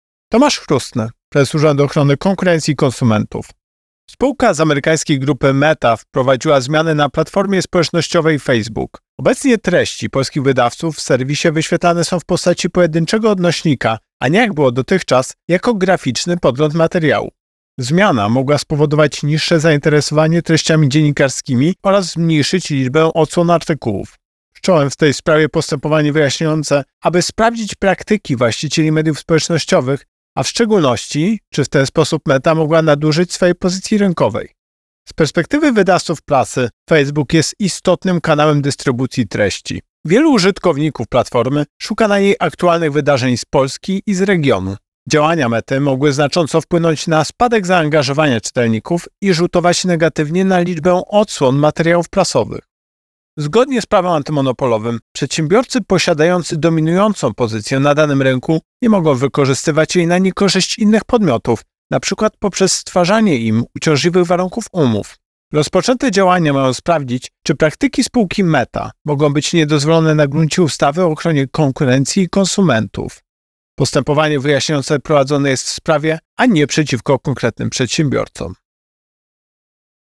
Wypowiedź Prezesa UOKiK Tomasza Chróstnego Postępowanie wyjaśniające prowadzone jest w sprawie, a nie przeciwko konkretnym przedsiębiorcom.